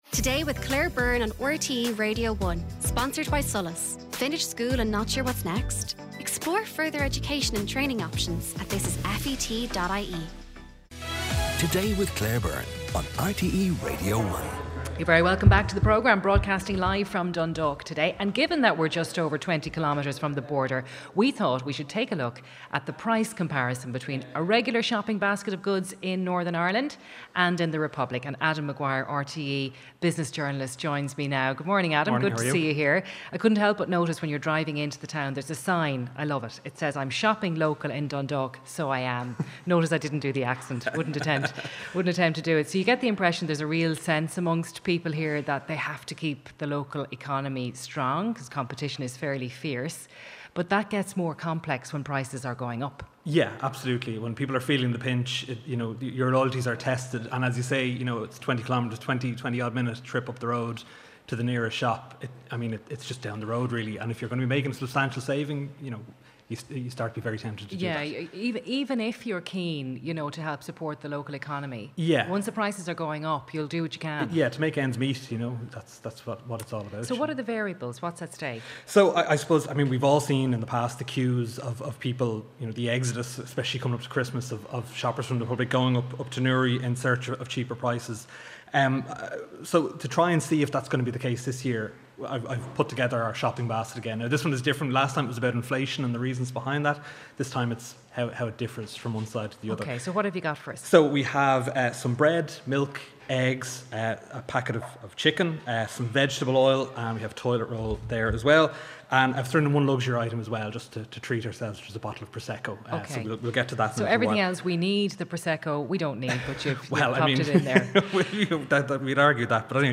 Live From Dundalk Part 4 by Today with Claire Byrne